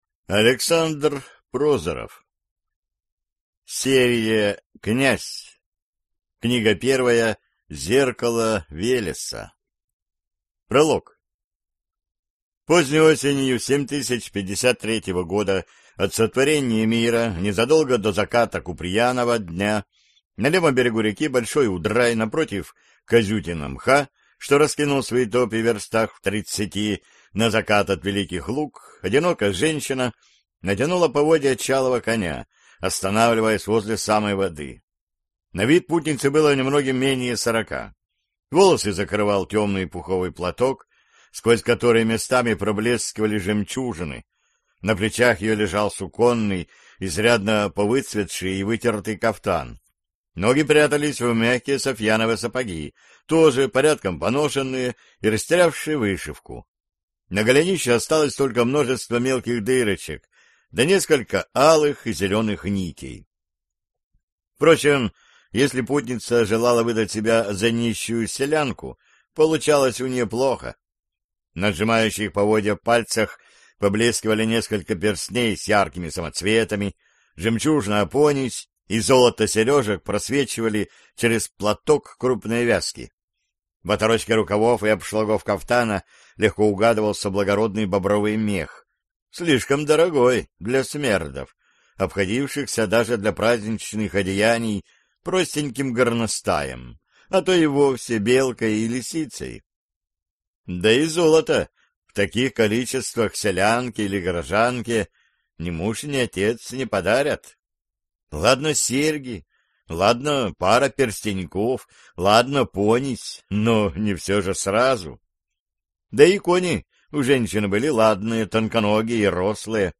Аудиокнига Зеркало Велеса | Библиотека аудиокниг